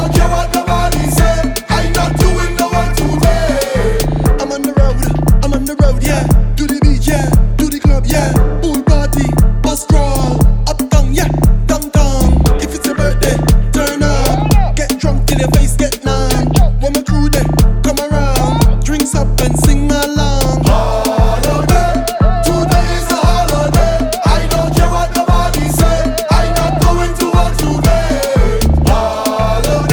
# Soca